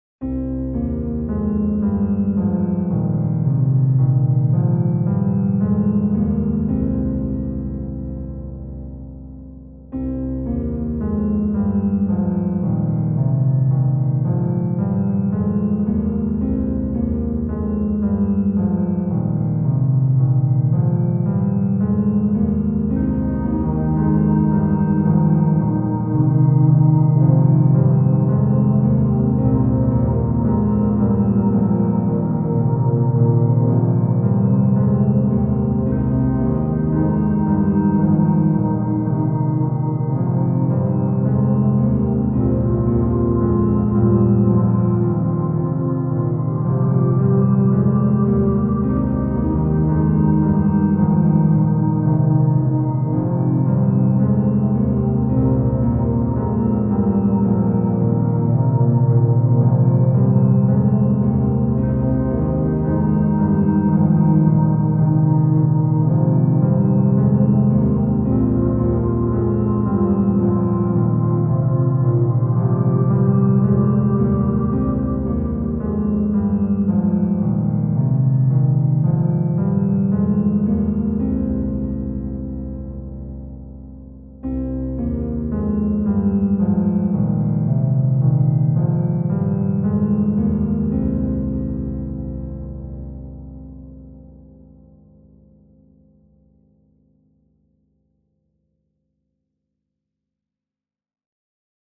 Ambo Piano